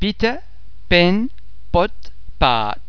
The French [p] in contrast to the English [p] is produced with lots of facial muscle and lip tension, so as to create a very explosive [p] without the release of the tension into a more or less audible aspiration.
·the same English words pronounced by the French speaker :